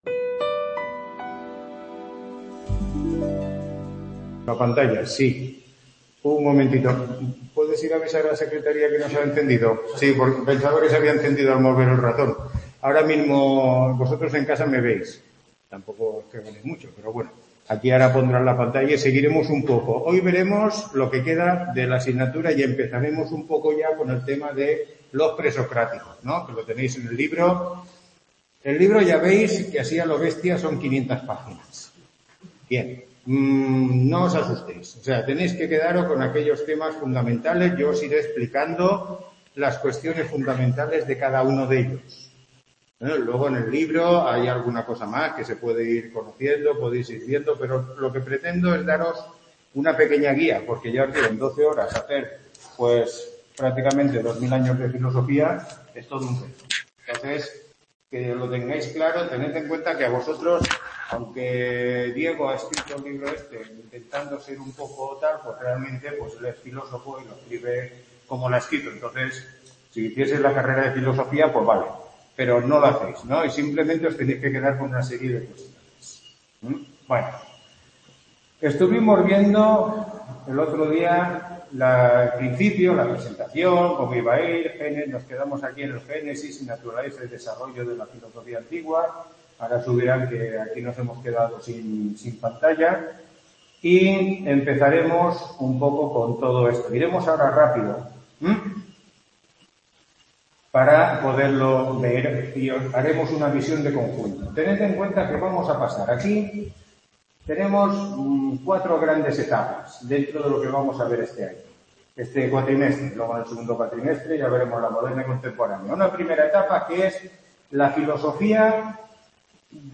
Tutoría 2